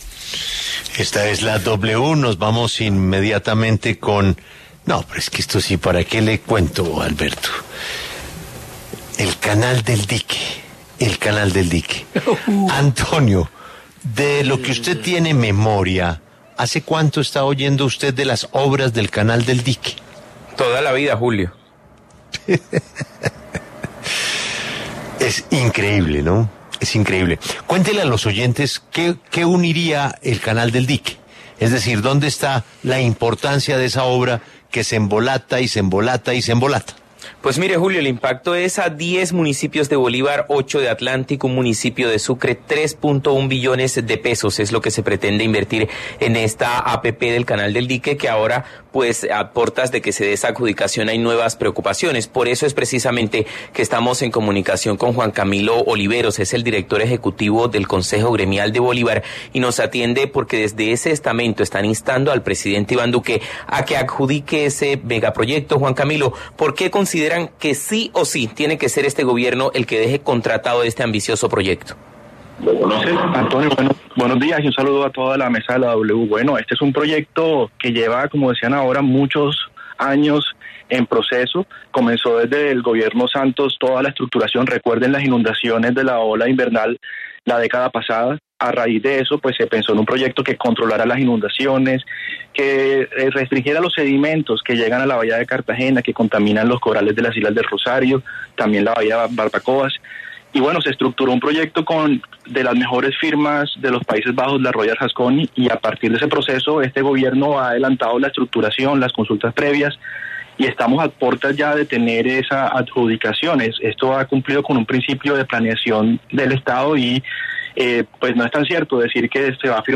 Un vocero de los gremios de Bolívar y un líder de las comunidades cercanas al Canal del Dique hablaron en La W del proyecto que impactará a tres departamentos.